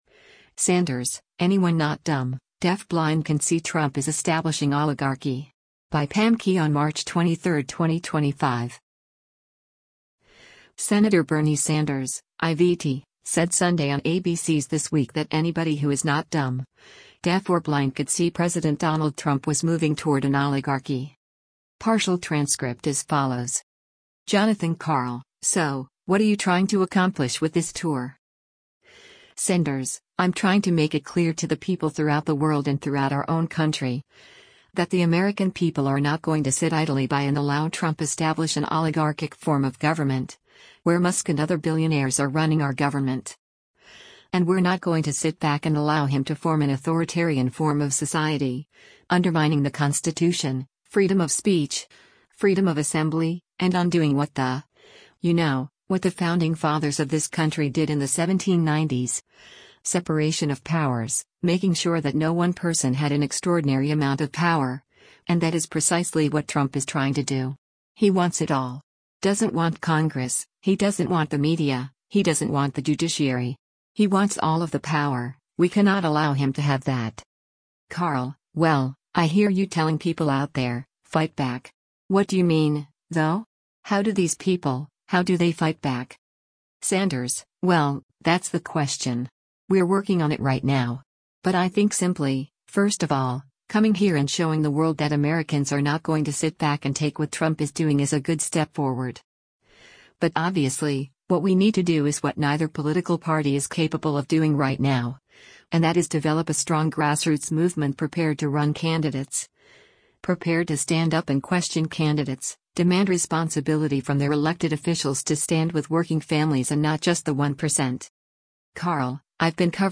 Senator Bernie Sanders (I-VT) said Sunday on ABC’s “This Week” that “anybody who is not dumb, deaf or blind” could see President Donald Trump was moving toward an oligarchy.